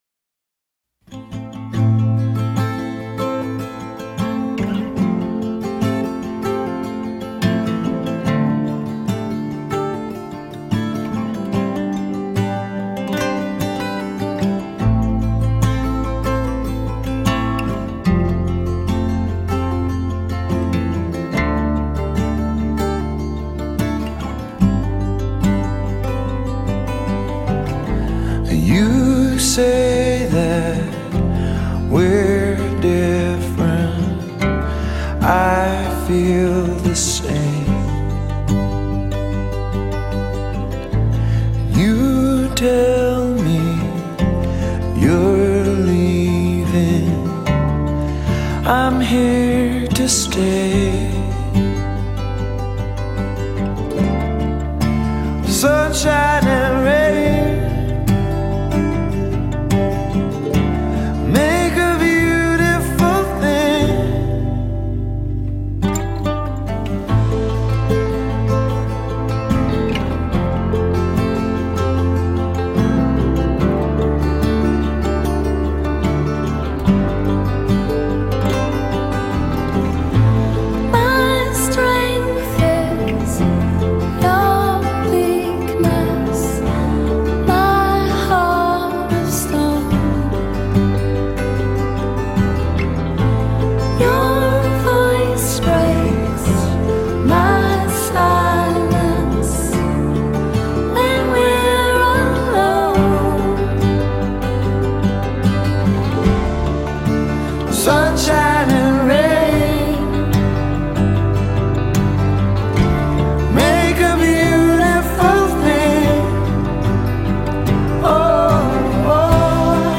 веселая музыка (открыта)